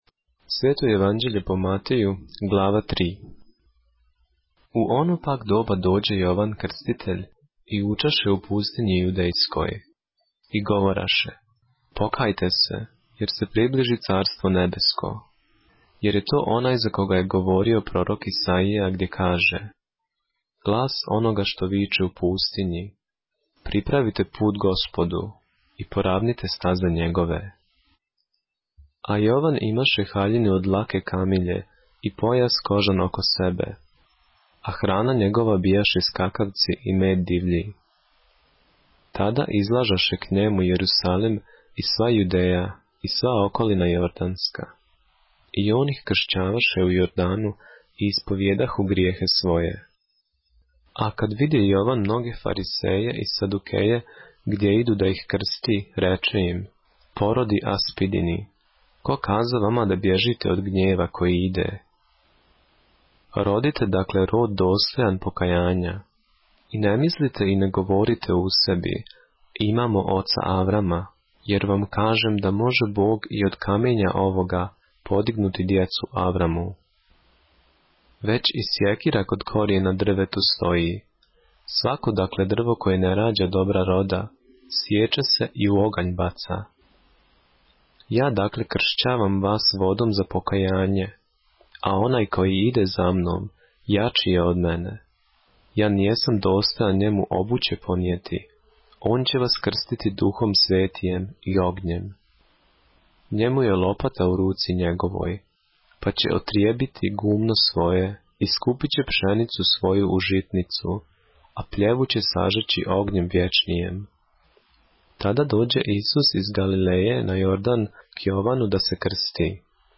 поглавље српске Библије - са аудио нарације - Matthew, chapter 3 of the Holy Bible in the Serbian language